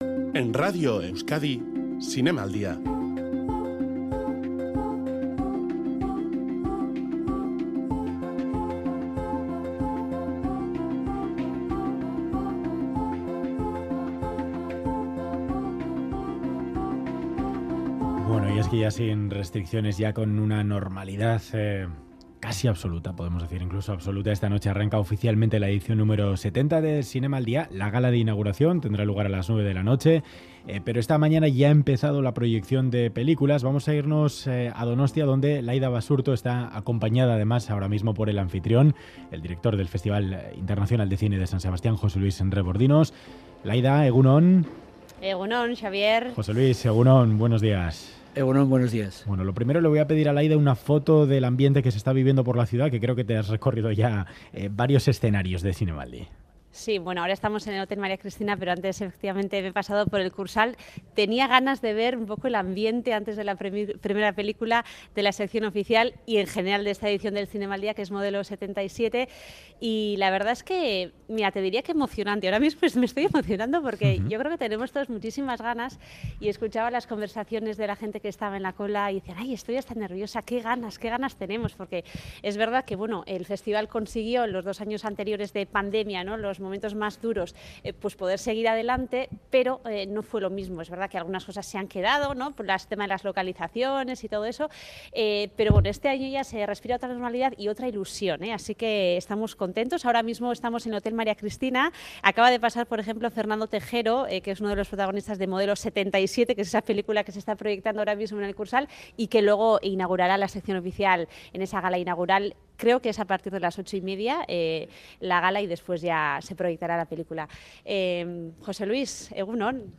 Hoy vuelve el Zinemaldia a San Sebastián con mayor presencia del cine vasco como confirmaba en Radio Euskadi el director del festival.